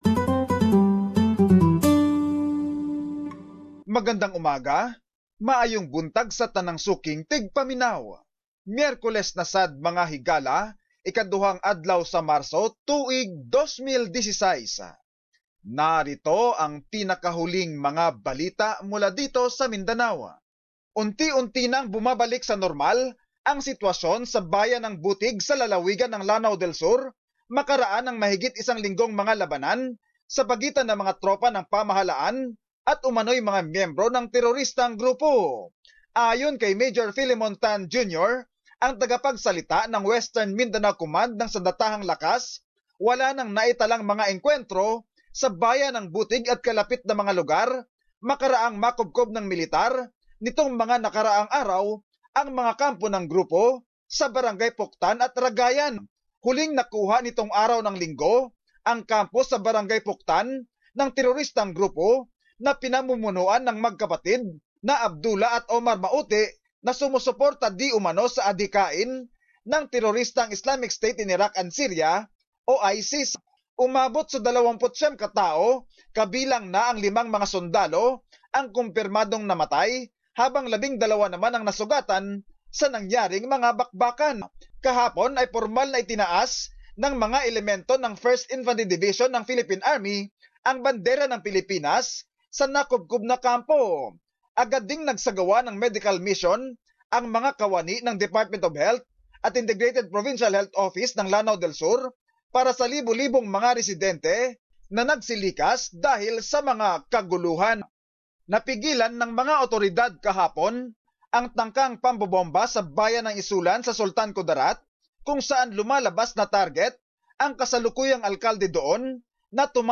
Summary of latest news in the region